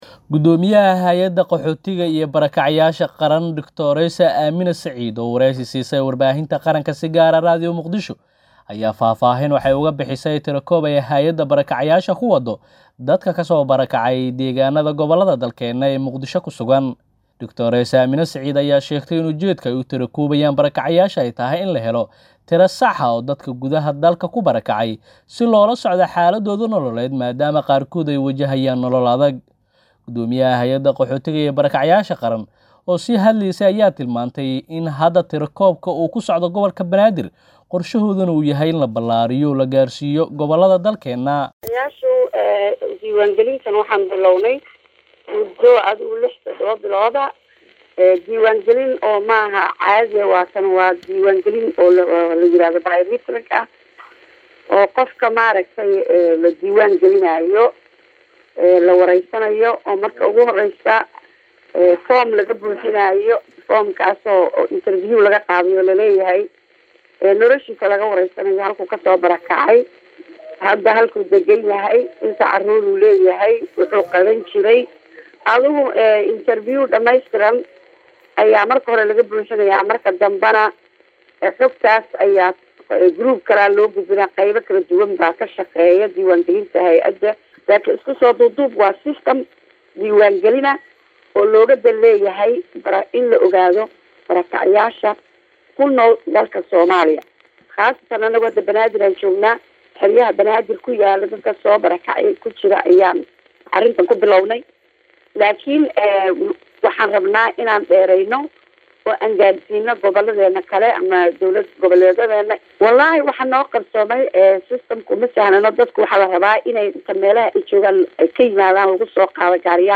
Guddoomiyaha hey’adda qaxootiga iyo barakacayaasha Qaran Drs. Aamina Saciid oo wareysi siisay warbaahinta qaranka si gaar ah Radio Muqdisho ayaa faahfaahin ka bixisay tirakoob ay hey’addu barakacayaasha ku wado dadka ka soo barakacay deeganadooda.